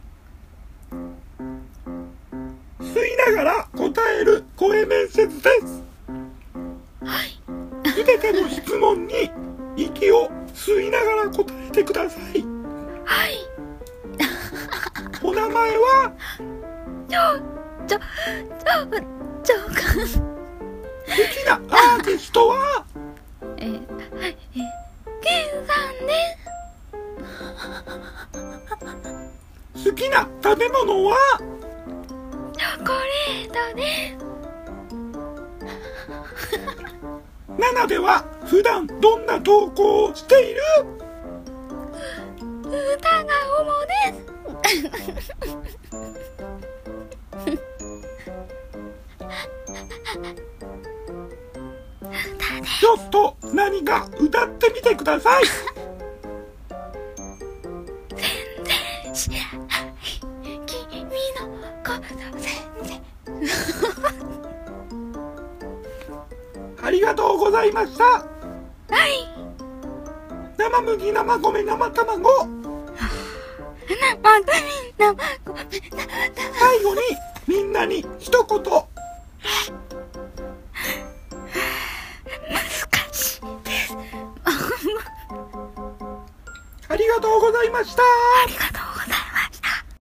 全部吸いながら答える声面接